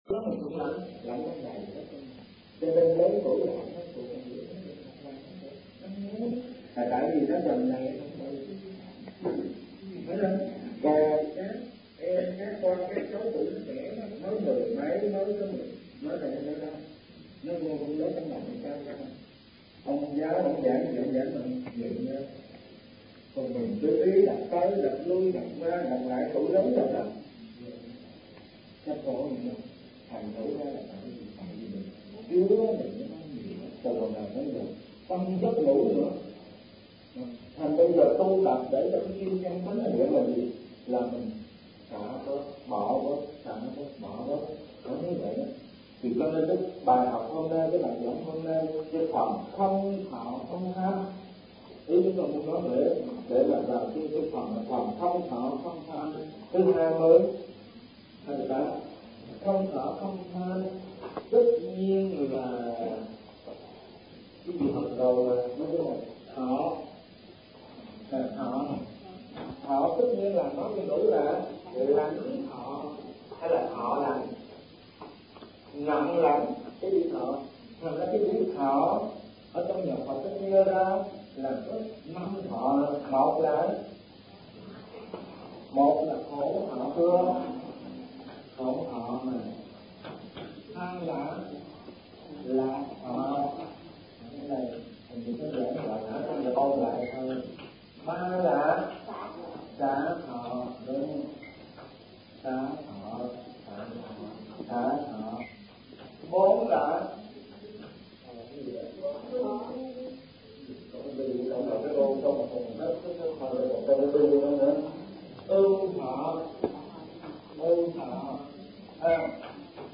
Kinh Giảng Kinh Kim Cang